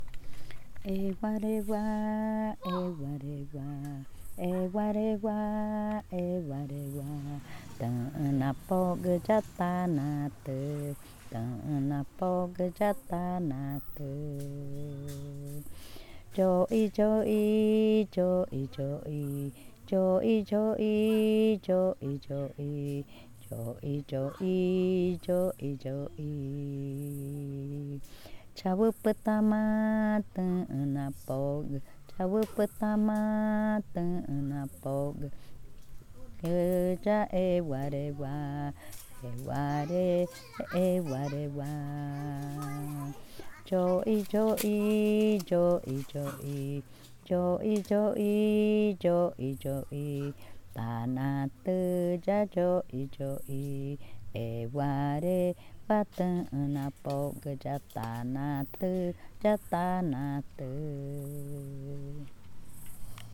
Cushillococha
Canción infantil sobre Yoxi